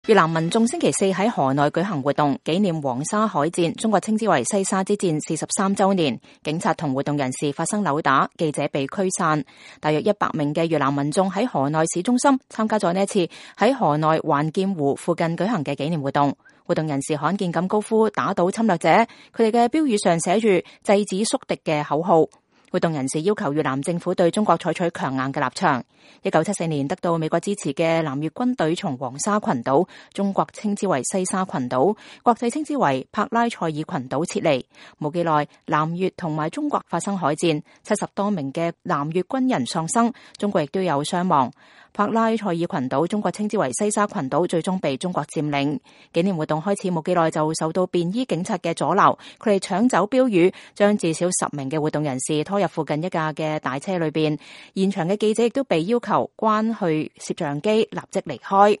越南民眾星期四（1月19日）在河內舉行活動，紀念黃沙海戰（中國稱西沙之戰）43周年。員警跟活動人士發生扭打，記者被驅散。